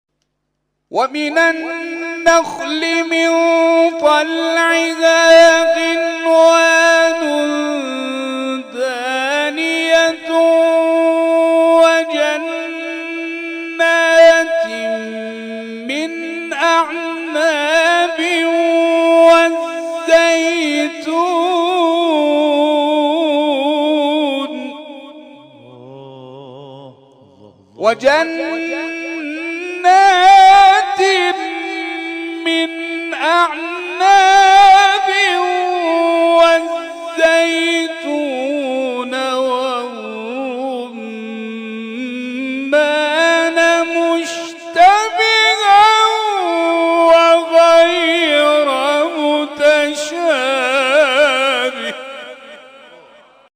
فرازهایی از محفل این هفته آستان عبدالعظیم(ع)
گروه جلسات و محافل: محفل انس با قرآن این هفته آستان عبدالعظیم الحسنی(ع) با تلاوت قاریان ممتاز و بین‌المللی کشورمان برگزار شد.